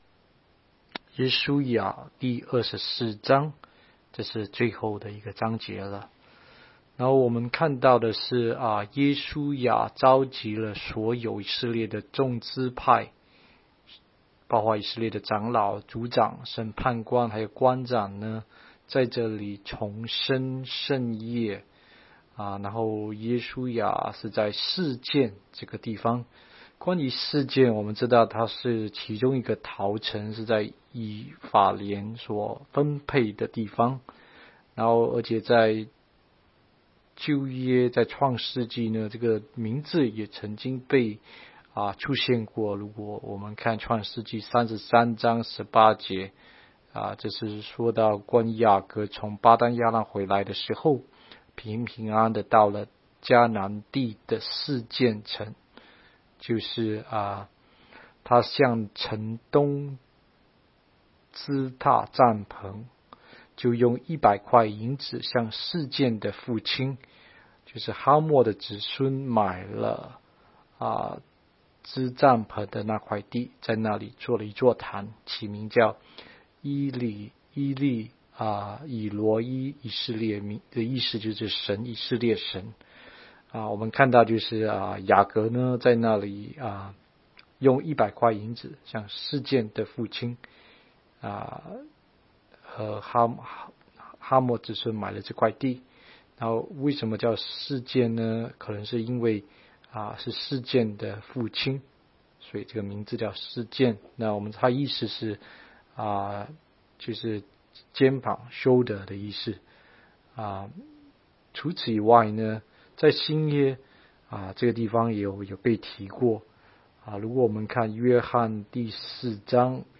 16街讲道录音 - 每日读经-《约书亚记》24章